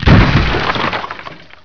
WOODBRK.WAV